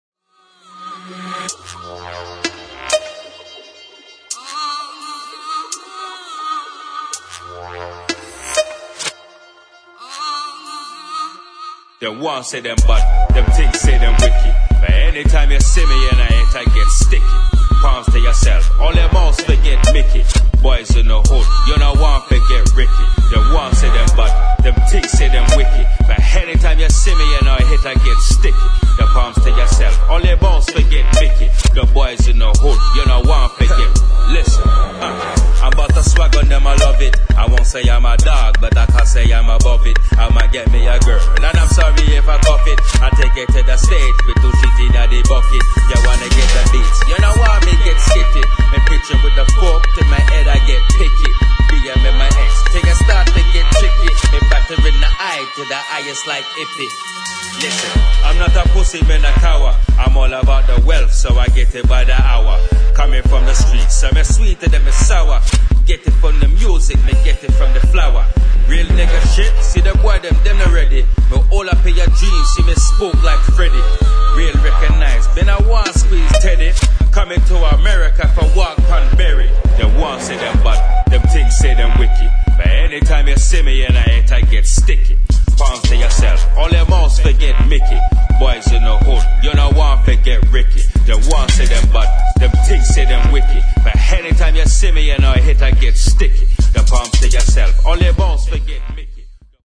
[ DUBSTEP ]